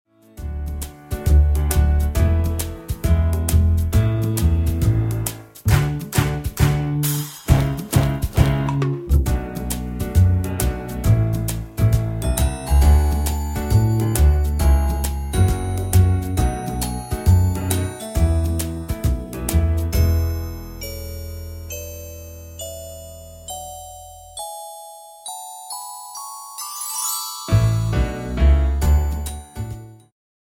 伴奏音樂